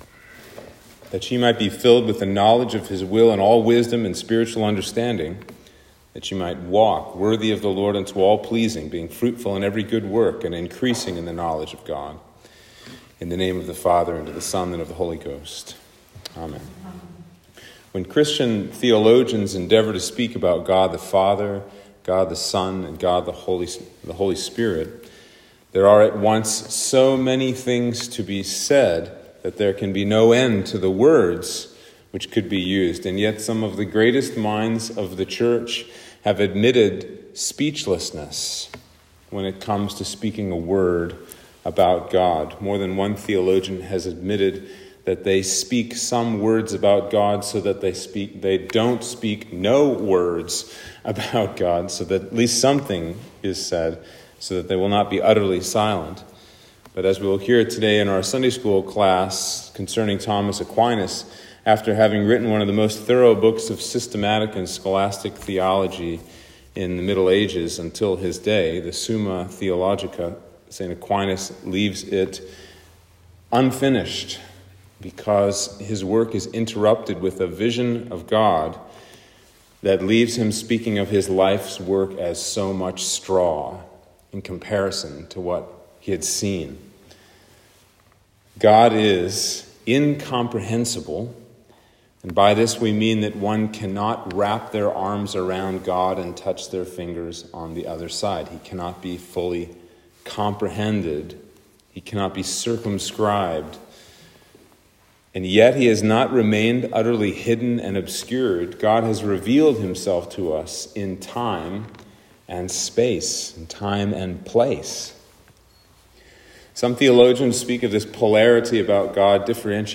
Sermon for Trinity 24